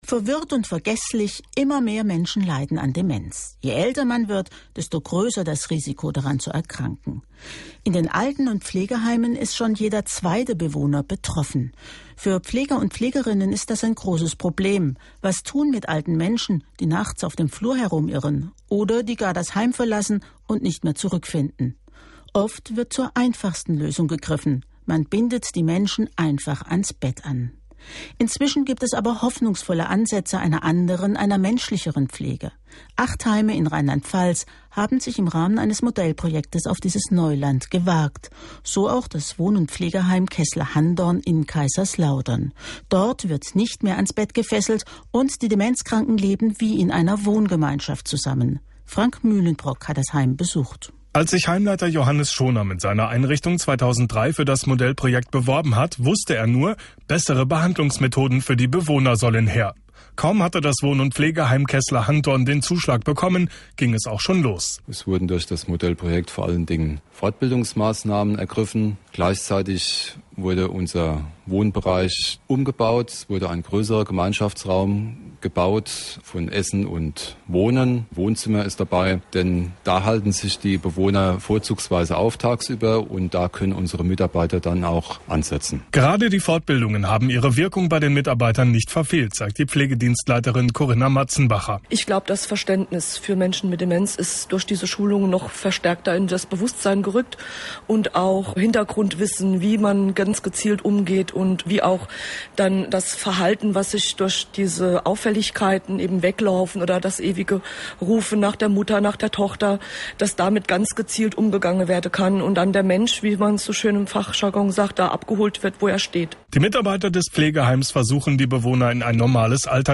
Beitrag im SWR
Am 26. März 2007 wurden die Ergebnisse des Modellprojektes "Optimierung der Versorgung von Menschen mit Demenz in stationären Pflegeeinrichtungen in Rheinland-Pfalz“ bei einer Pressekonferenz des Ministeriums für Arbeit, Soziales, Gesundheit, Familie und Frauen (MASGFF) in Mainz vorgestellt. Der SWR produzierte aus diesem Anlass einen Beitrag, der im SWR1 und SWR4 gesendet wurde.